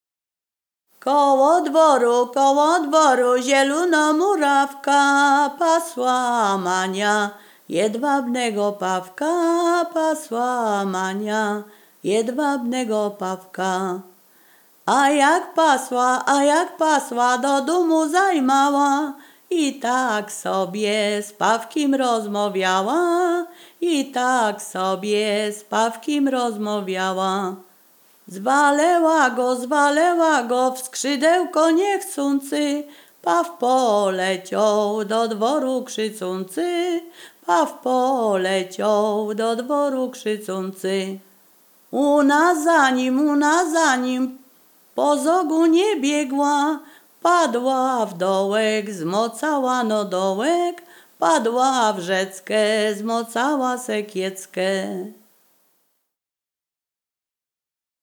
Ziemia Radomska
Przyśpiewki
liryczne obyczajowe